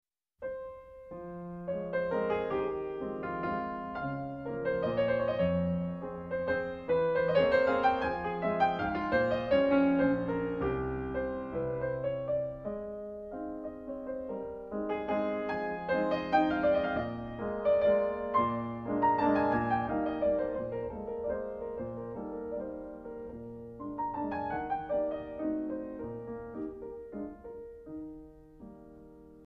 F minor